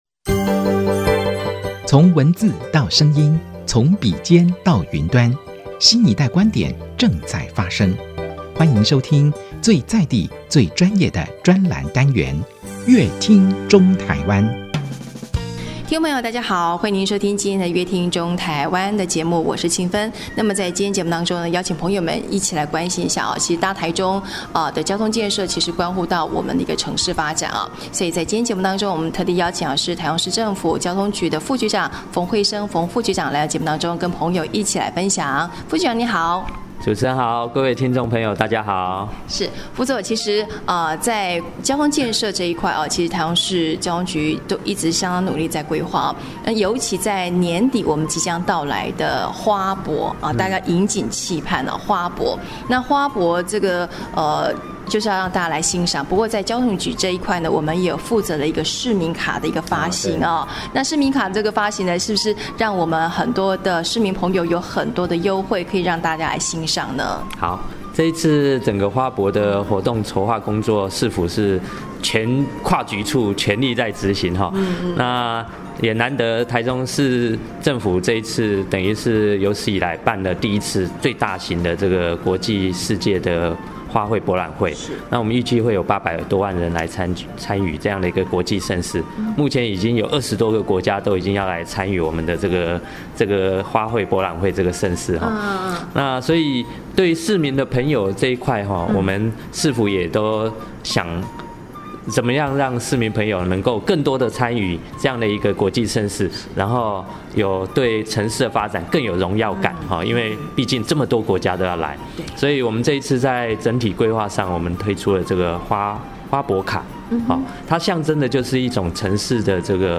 本集來賓：臺中市政府交通局馮輝昇副局長 本集主題：「臺中捷運綠線 瞄準109年全線通車」 本集內容： 走在台中